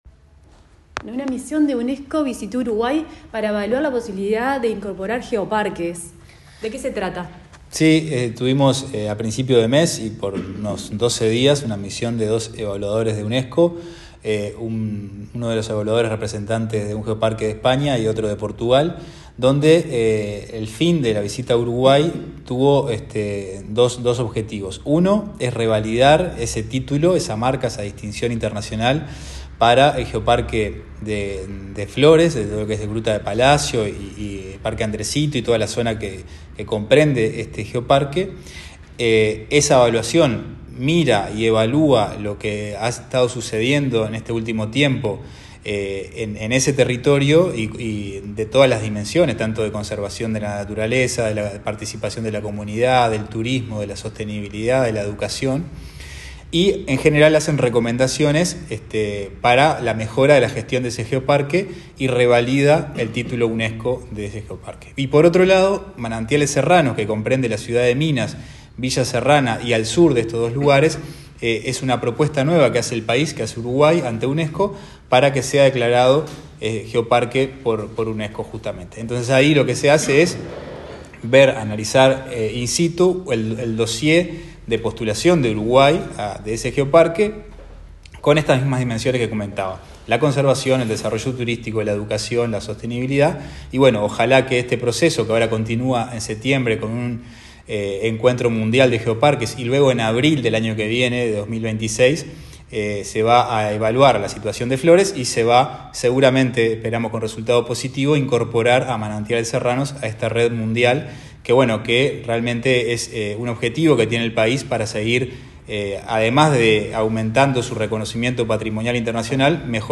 Declaraciones del director nacional de Turismo, Cristian Pos